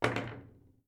paddle_drop_converted.wav